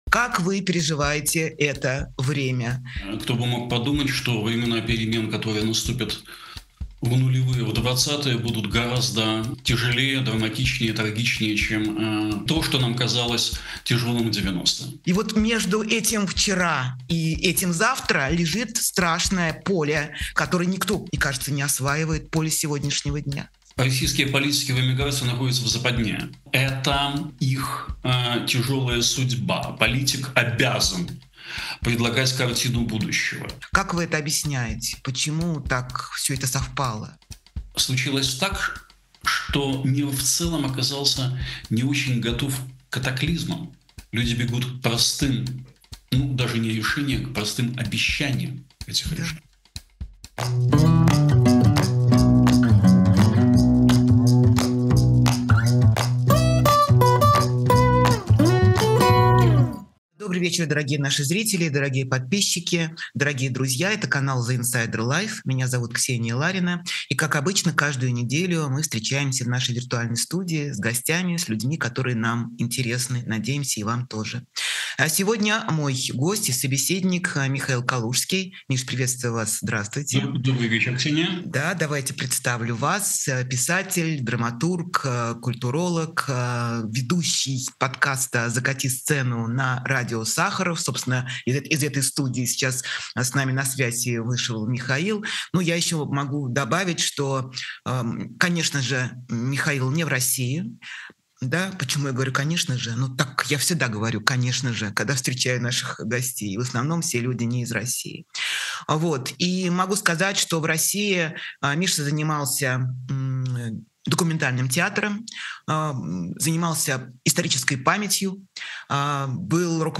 Эфир ведёт Ксения Ларина